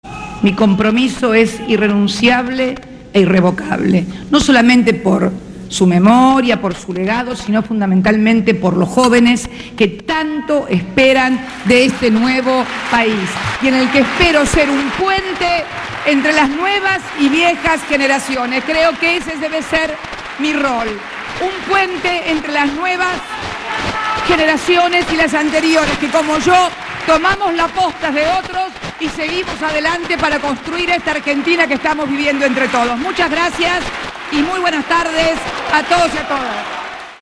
En la Casa de Gobierno, en el marco de la inauguración de la TV Digital para Jujuy y Entre Ríos, la Presidenta anunció su candidatura presidencial.